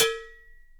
percussion01.wav